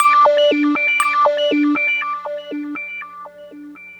Galaxy Stab.wav